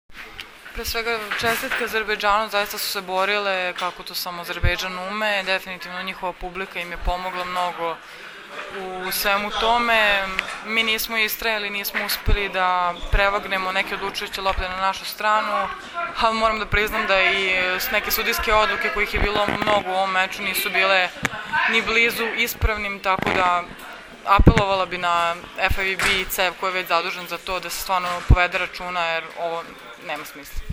IZJAVA JOVANE BRAKOČEVIĆ